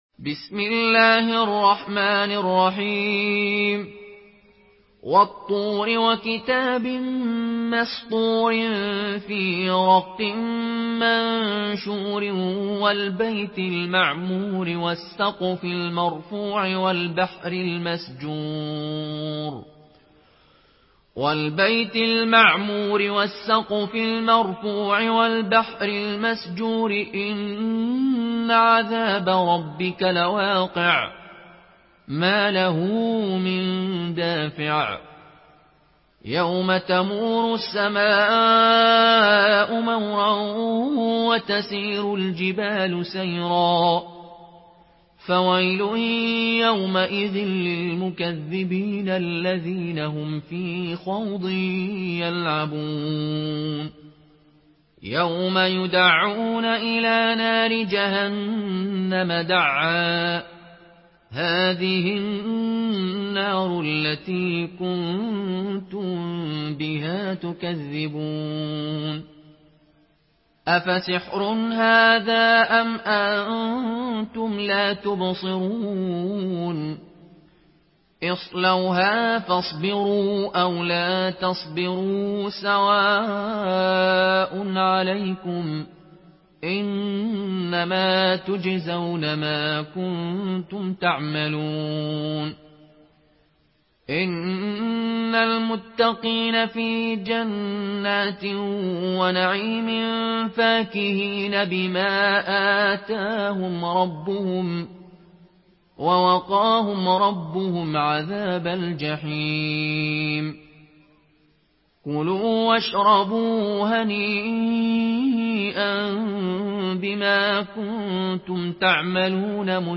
in Qaloon An Nafi narration
Murattal